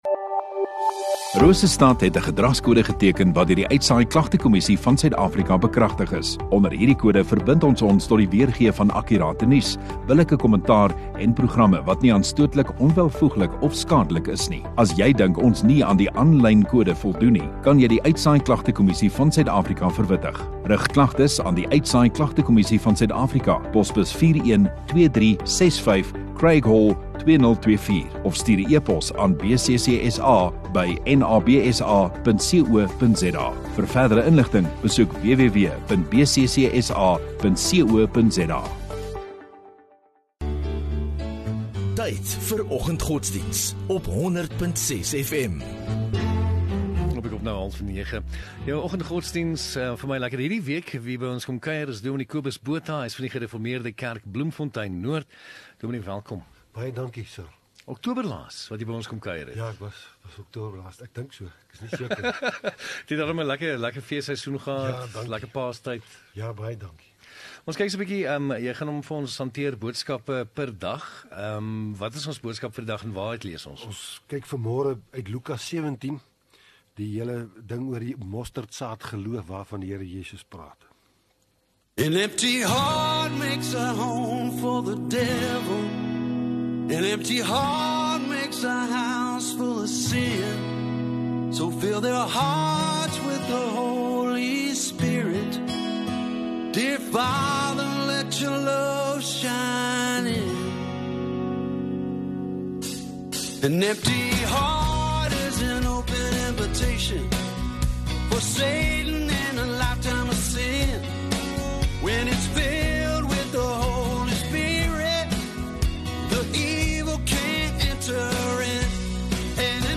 15 Apr Maandag Oggenddiens